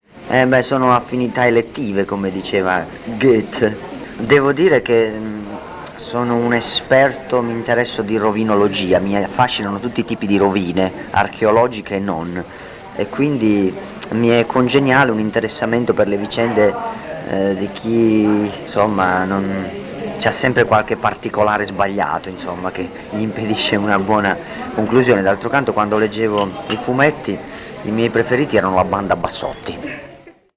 frammenti audio: Vinicio Capossela a Radio West
Dichiarazioni raccolte in occasione del concerto di Moncalvo (26 marzo 1996), quando l’organizzazione della serata di Ricaldone era ai suoi inizi. Con la sua tipica ironia, Capossela affronta vari argomenti che ascoltiamo in sequenza: i personaggi delle sue canzoni…